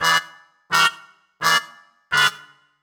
GS_MuteHorn_85-E.wav